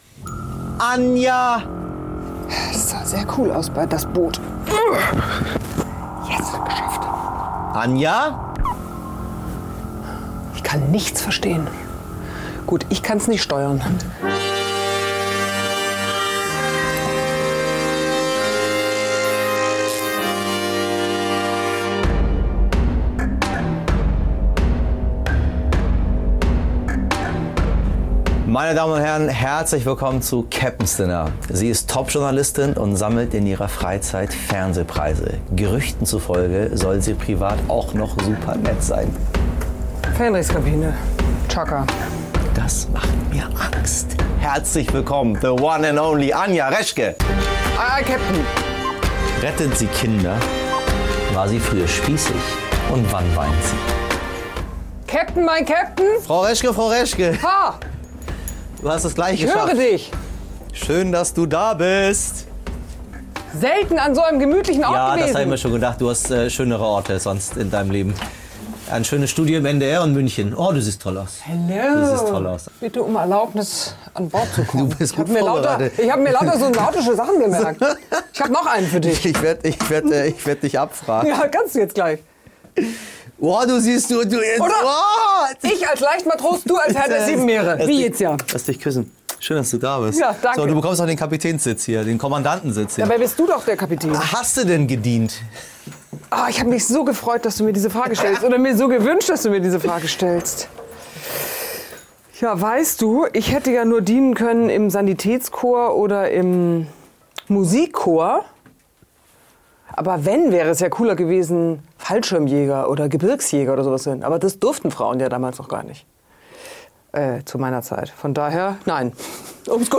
Bei Michel Abdollahi im U-Boot ist die Journalistin und Panorama-Moderatorin Anja Reschke zu Gast.